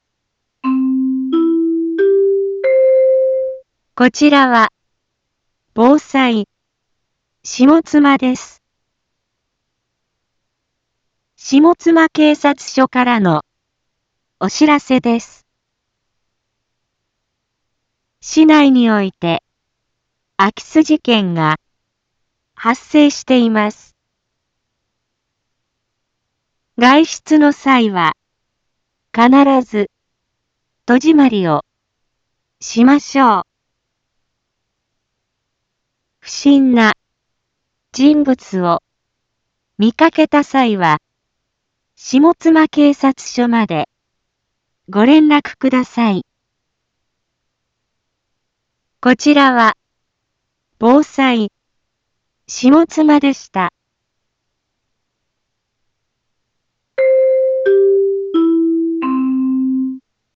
一般放送情報
Back Home 一般放送情報 音声放送 再生 一般放送情報 登録日時：2024-02-16 10:01:05 タイトル：住居侵入事件の発生について インフォメーション：こちらは、防災、下妻です。